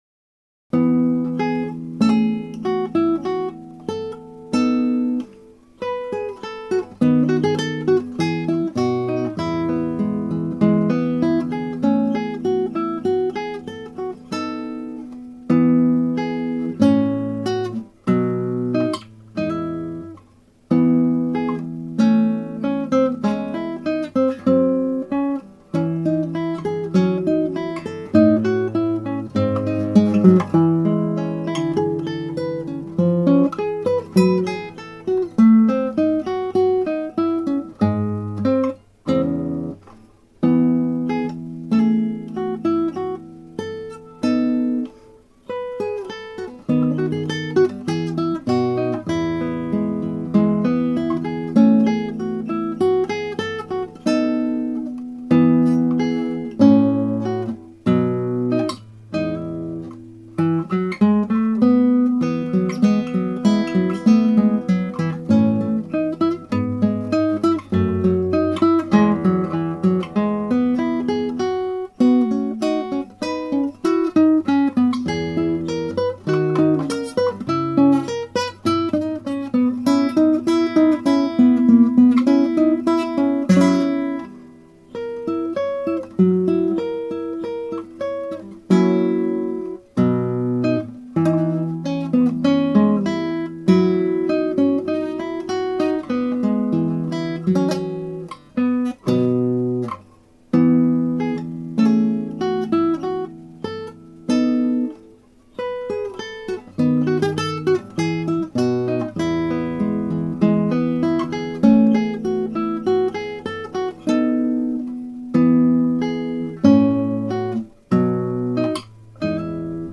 Bach : リュート組曲4番よりロンド風ガボット (アマチュアギター演奏)
(アマチュアのクラシックギター演奏です [Guitar amatuer play] )
大きなミスは直しましたが57小節でミスタッチして穴があいたような違う音を出してしまっています。その他、小さなキズはあちこちにちりばめられています。
テンポもほぼ同じで遅いですが初録よりはスムーズな流れになったように思います。